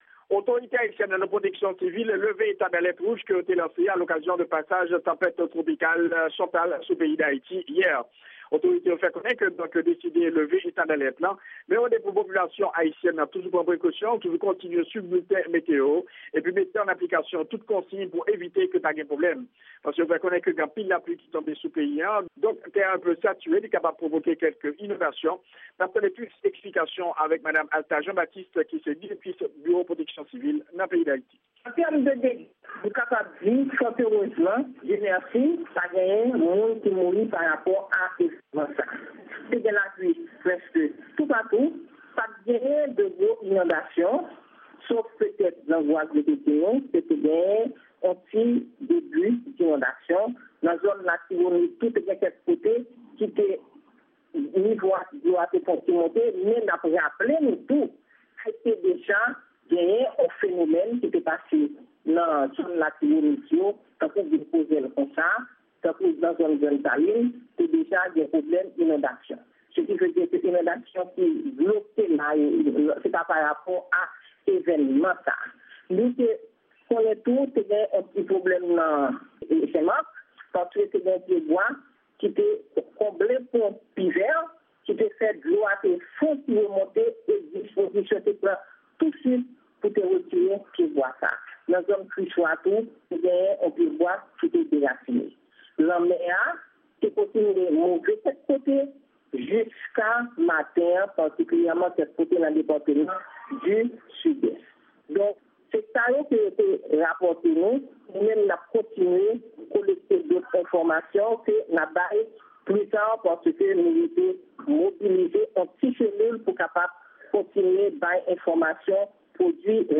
Yon repòtaj Lavwadlamerik